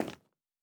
added stepping sounds
Tile_Mono_01.wav